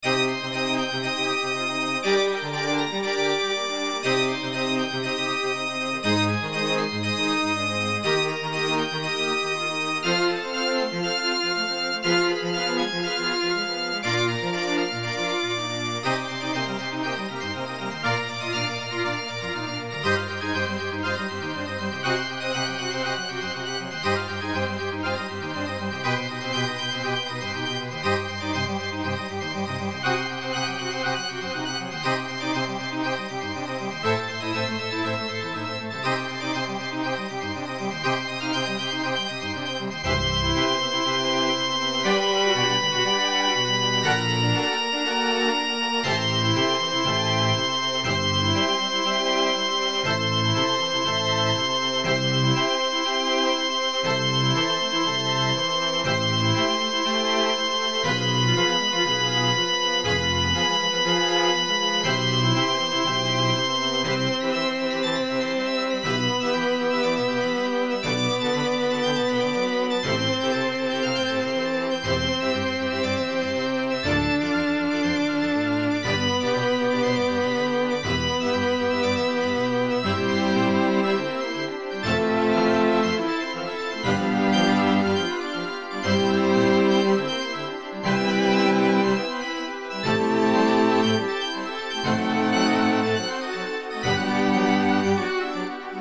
BGM
チャーチオルガン(パイプ)、バイオリン、ビオラ、チェロ